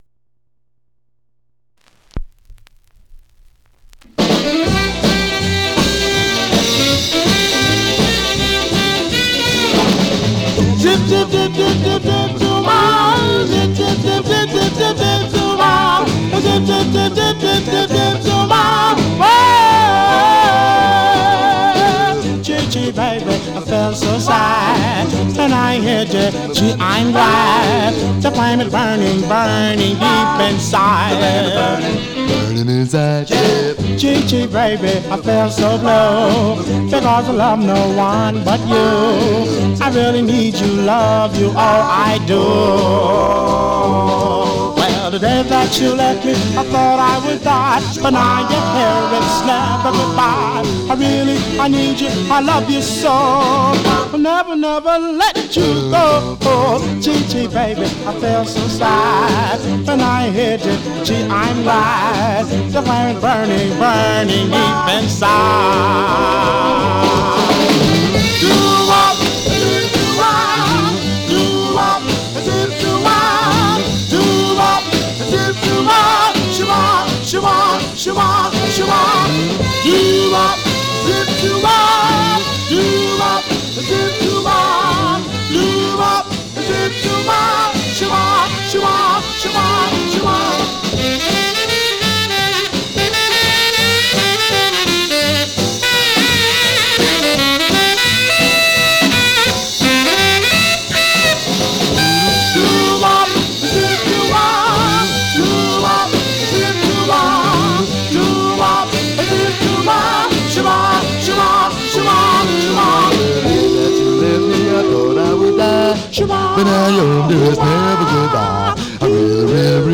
Stereo/mono Mono
Male Black Group Condition